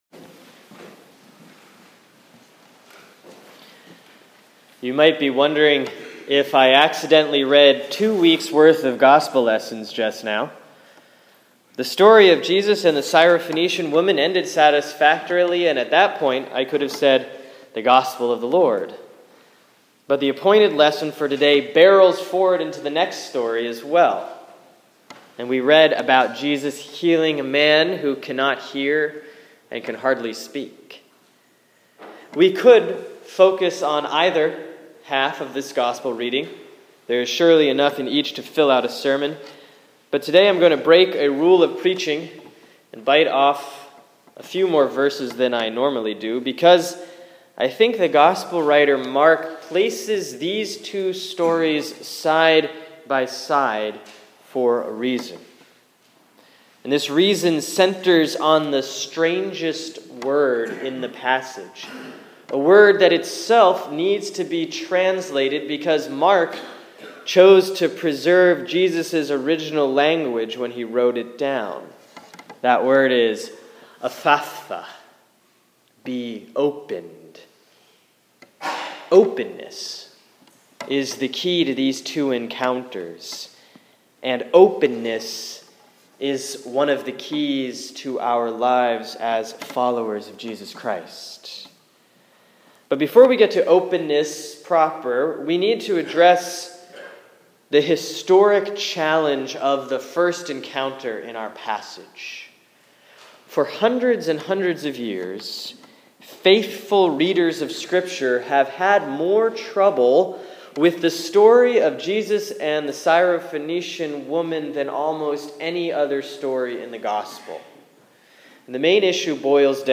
Sermon for Sunday, September 6, 2015 || Proper 18B || Mark 7:24-37